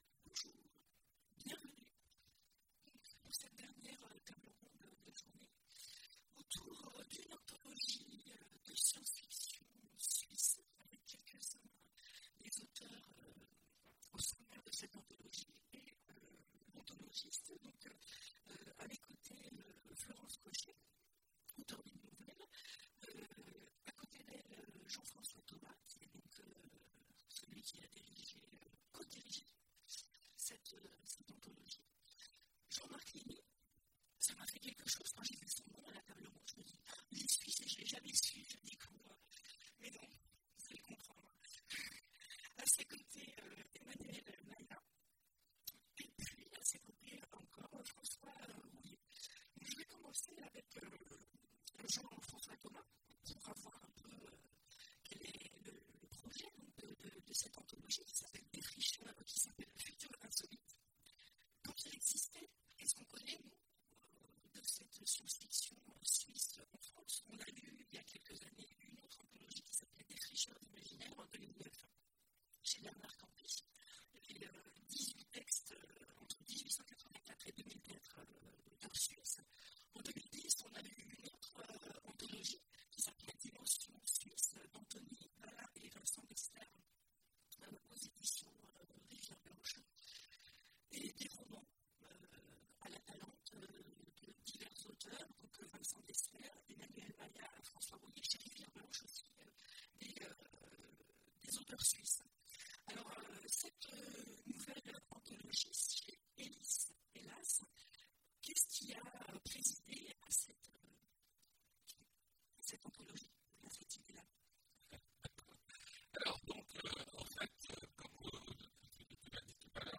Imaginales 2016 : Conférence Futurs insolites